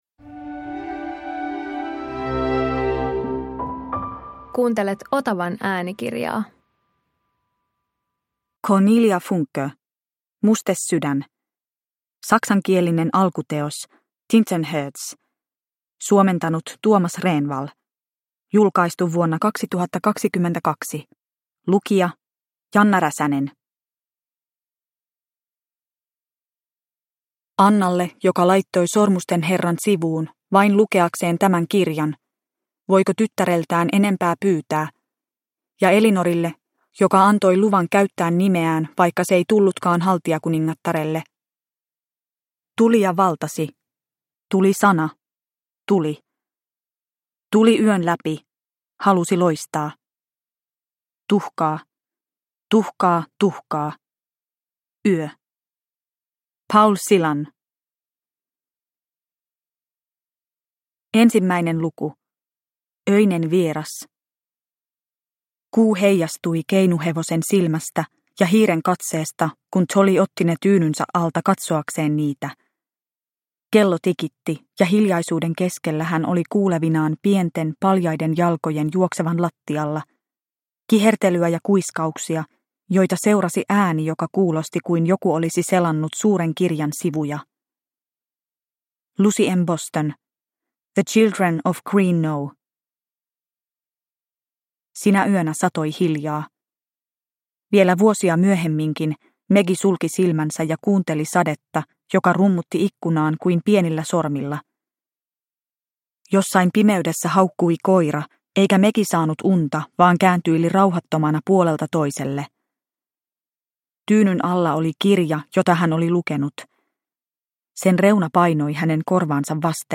Mustesydän – Ljudbok – Laddas ner